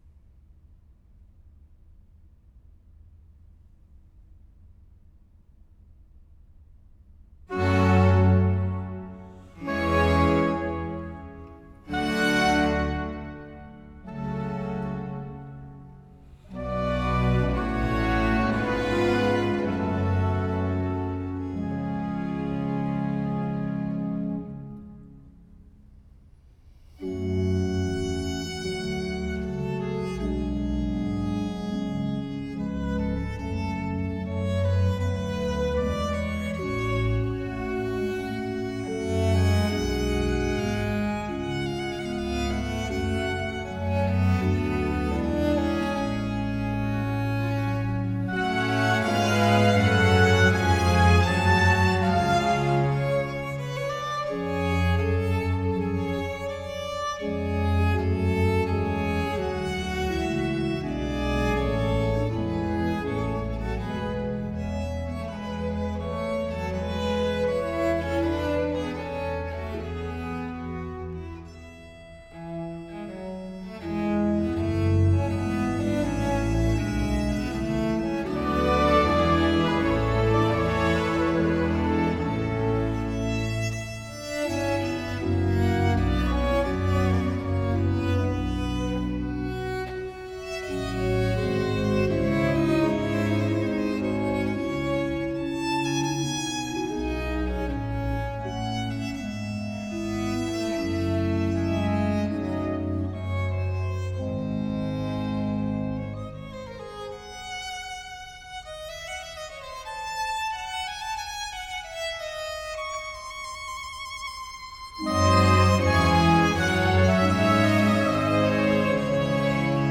Organ Concerto in G minor, Op.4 No.3 - I. Adagio